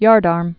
(yärdärm)